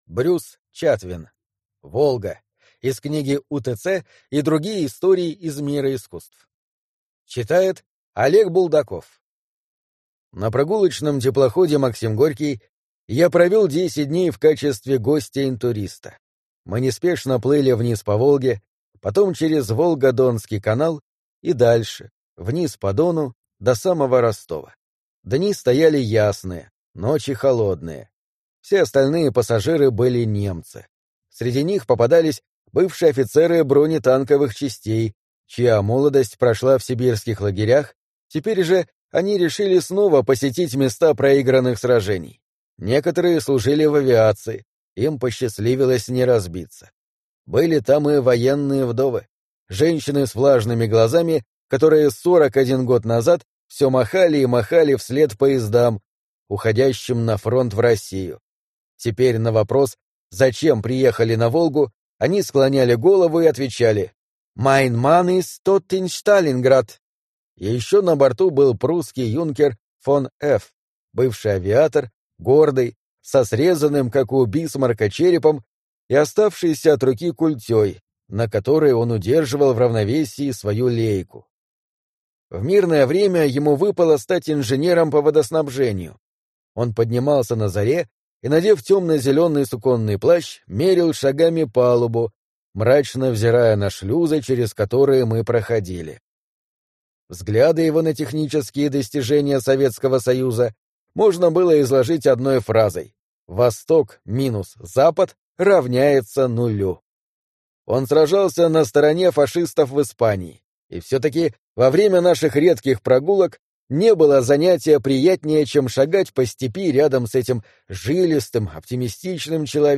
Аудиокнига Волга | Библиотека аудиокниг